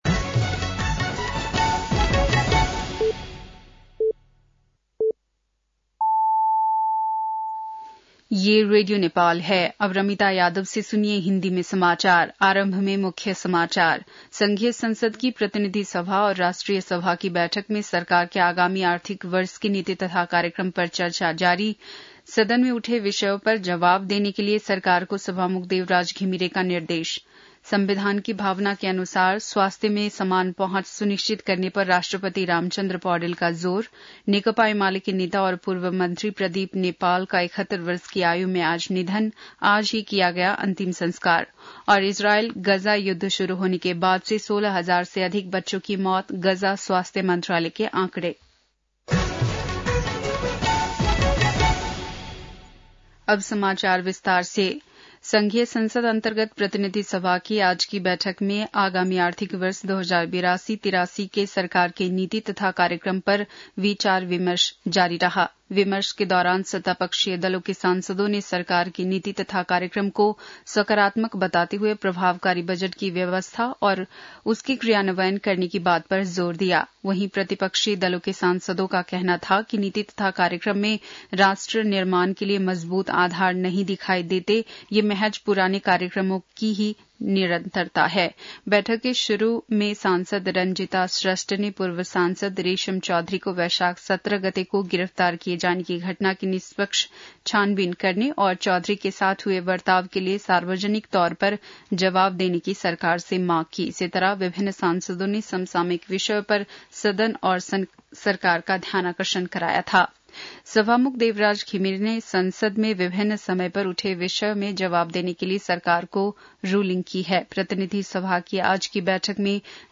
बेलुकी १० बजेको हिन्दी समाचार : २३ वैशाख , २०८२
10-pm-hindi-news-1-23.mp3